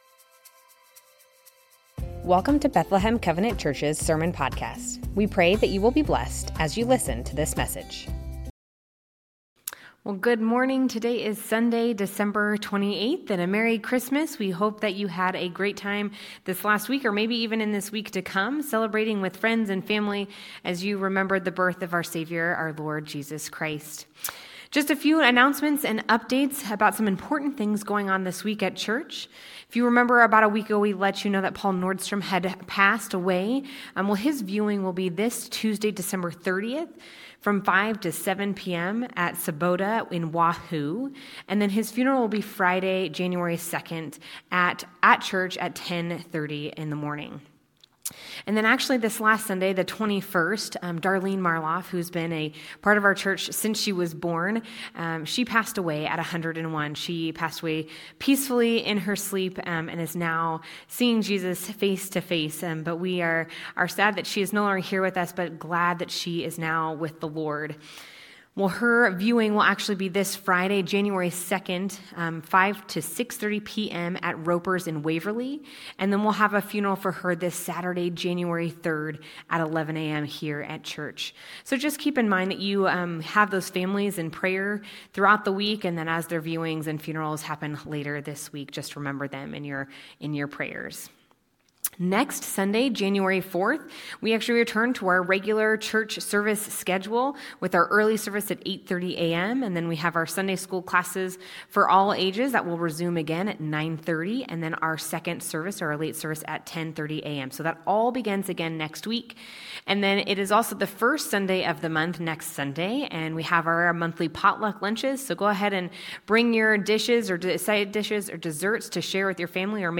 Bethlehem Covenant Church Sermons God's Great Mercy Dec 28 2025 | 00:31:07 1x 00:00 / 00:31:07 Subscribe Share Spotify RSS Feed Share Link Embed